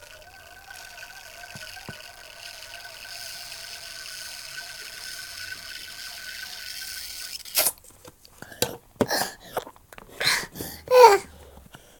babydrinks.ogg